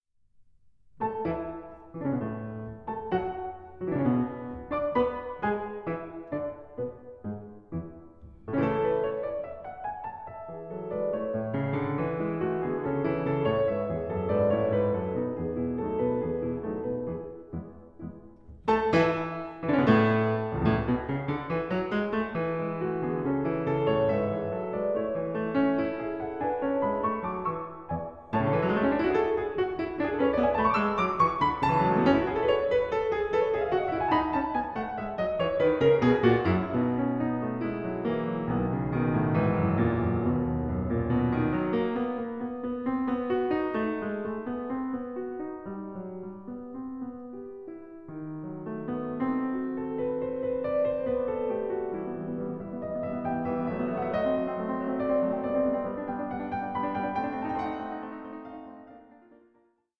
Piano Sonata No. 1 in F minor, Op. 2, No. 1